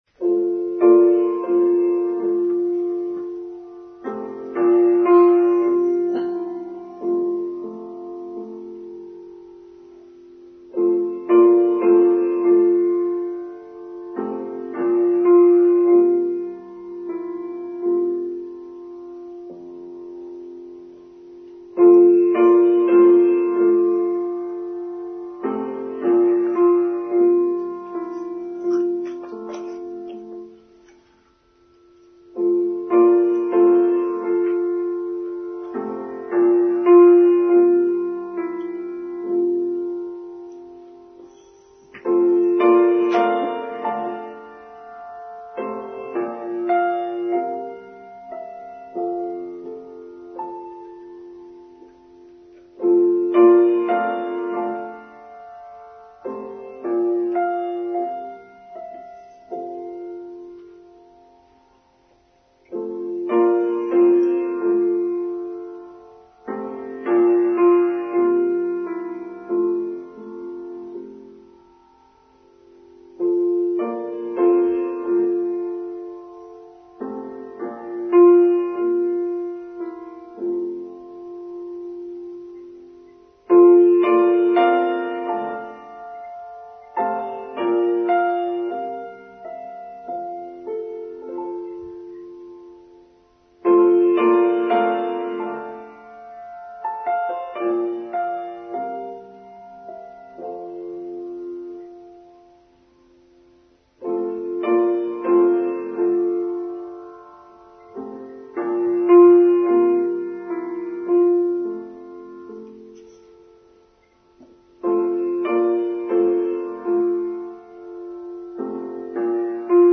Mothering Sunday: Online Service for Sunday 19th March 2023